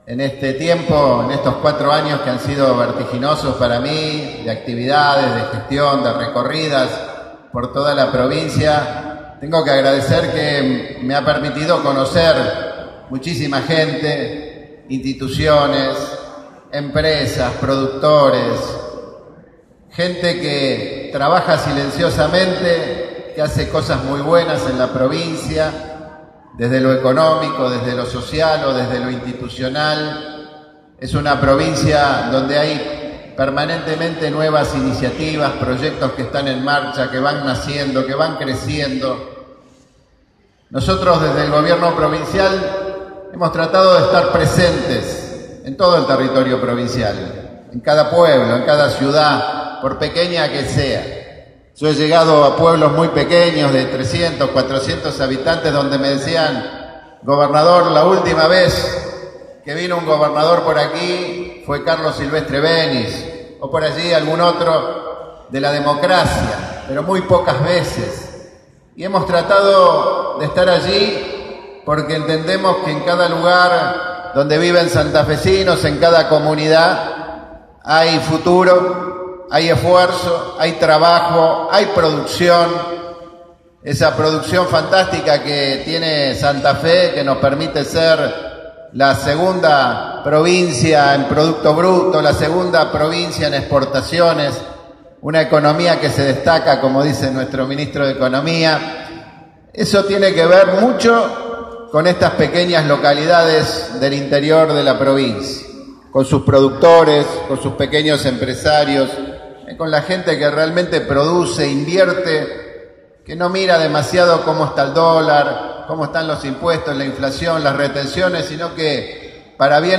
El gobernador Miguel Lifschitz participó este domingo de la sexta edición de la Fiesta de la Bagna Cauda, que se desarrolló en la localidad de Piamonte.